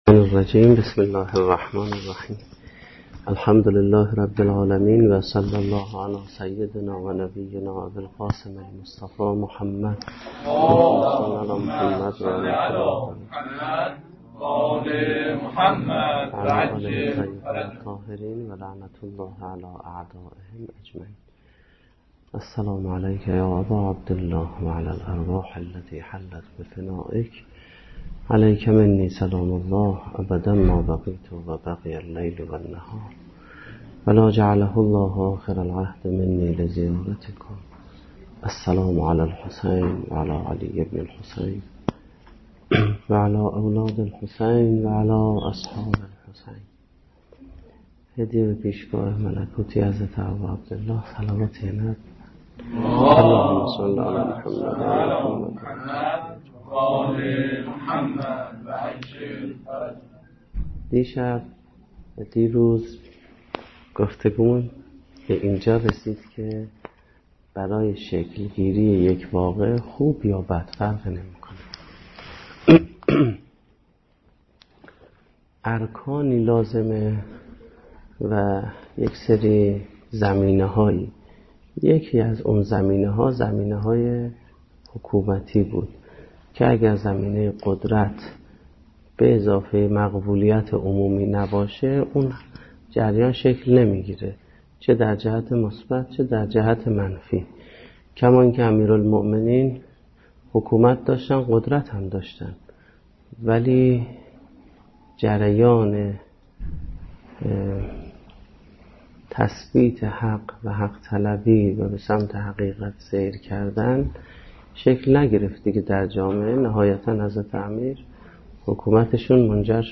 سخنرانی
ریشه های سیاسی حادثه عاشورا - شب سوم محرم الحرام 1436